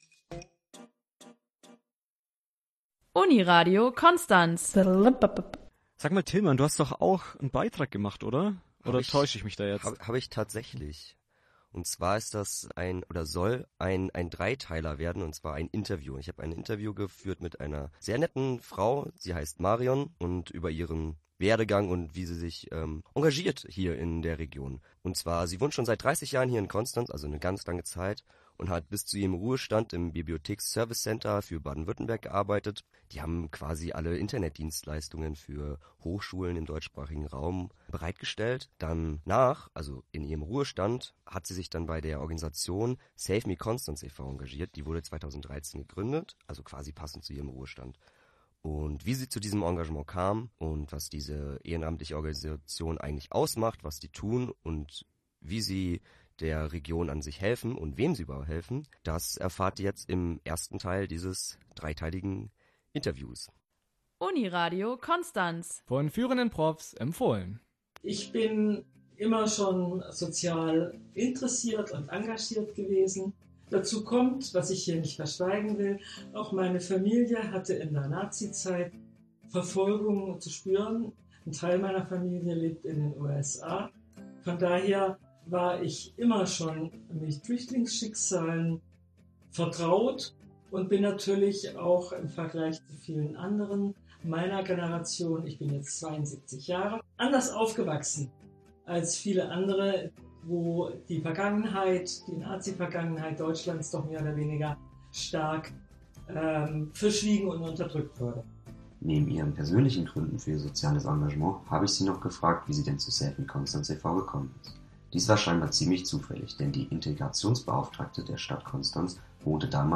Save me Interview im Uniradio Konstanz – Save me Konstanz
1.Teil_Interview_Save_Me_KN_Uniradio.mp3